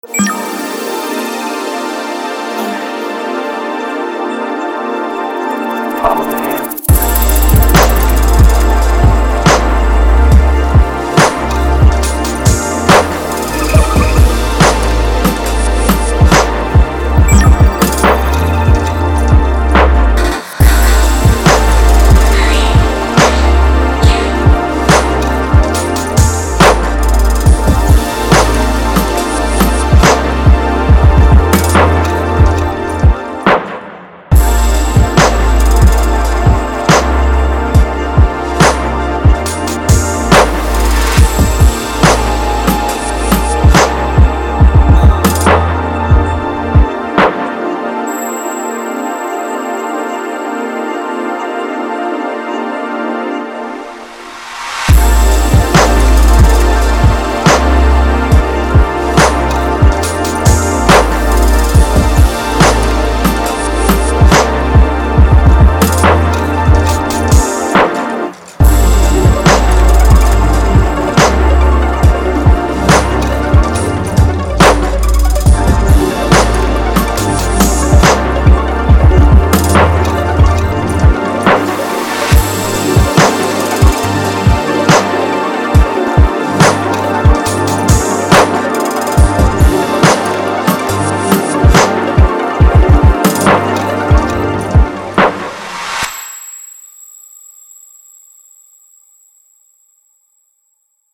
Жанр: Hip-Hop
Supertrap Атмосферный 140 BPM